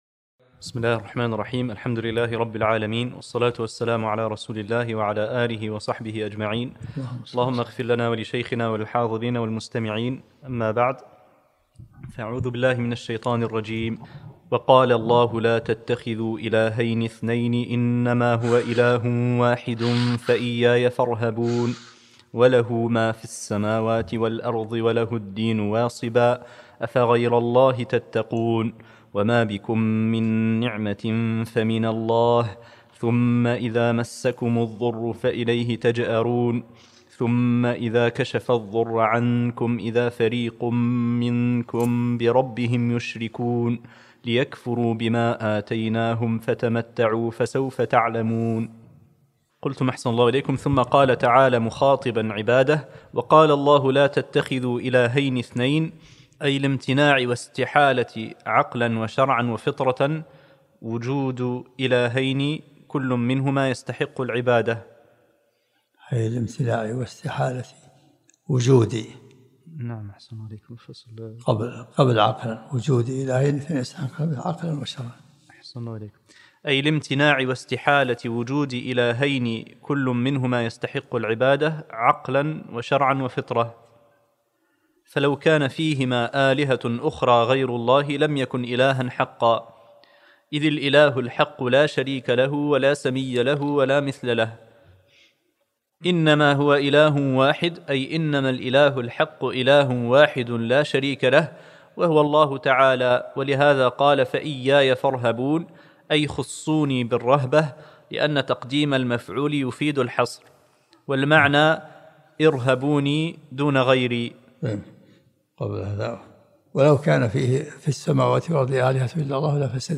الدرس الخامس من سورة النحل